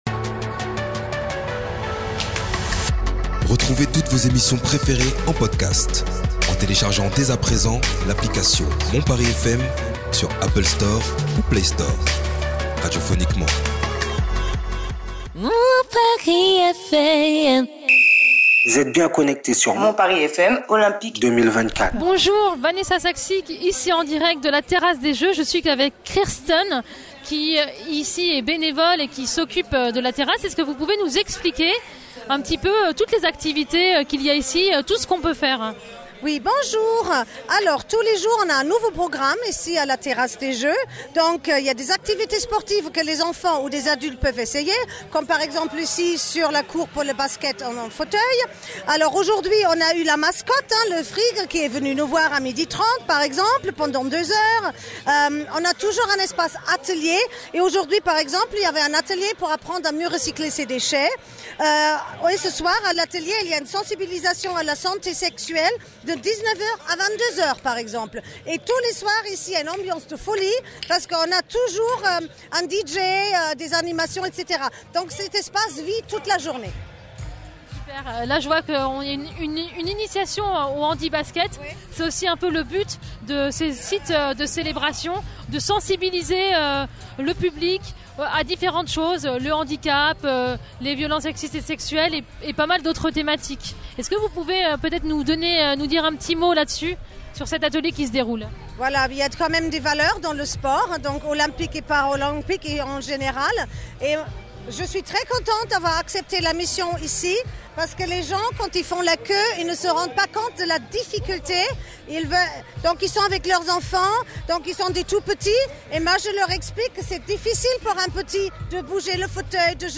La Terrasse des Jeux, c'est aussi des ateliers de sensibilisation aux différences, à l'inclusion, rencontre avec « Goodminton» l'association de badminton LGBT + et hétéro friendly Reportage